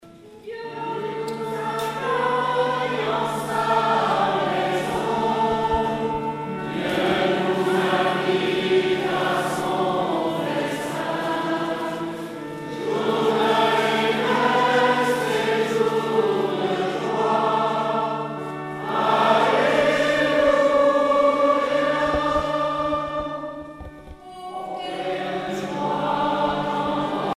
prière, cantique
Genre strophique
Pièce musicale éditée